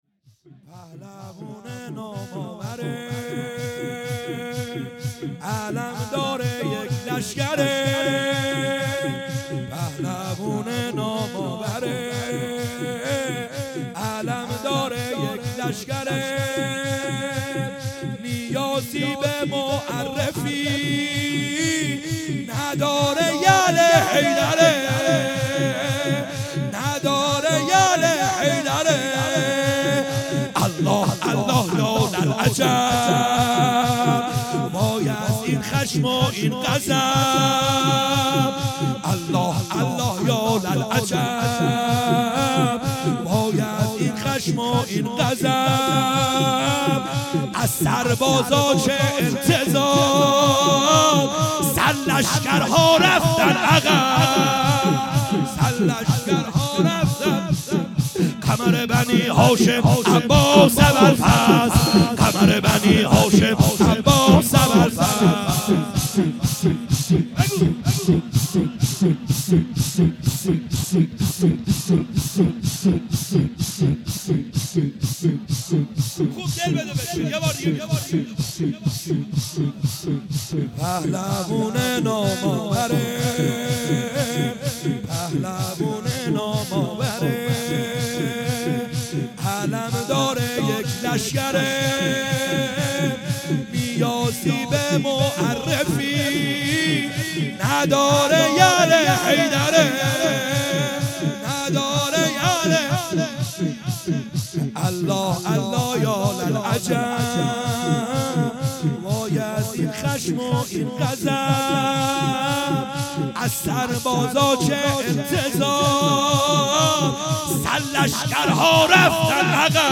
خیمه گاه - بیرق معظم محبین حضرت صاحب الزمان(عج) - شور | پهلوونه نام آوره